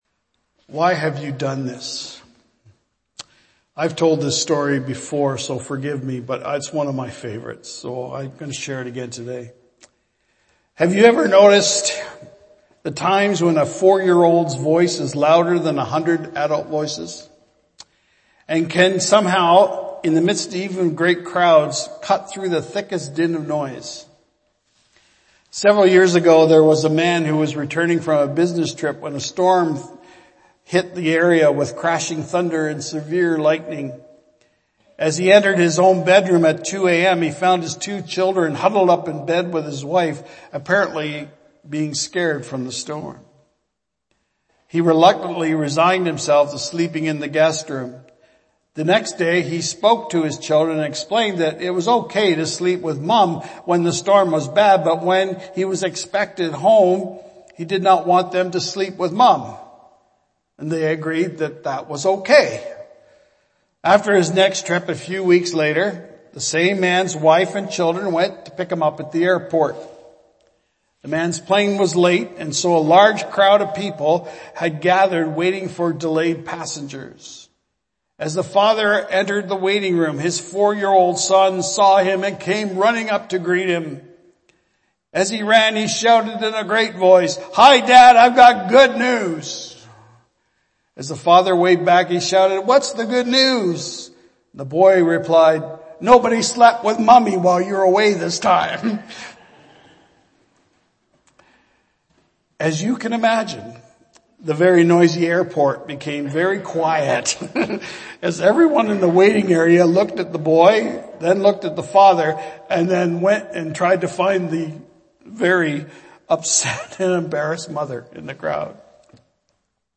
Archived Sermons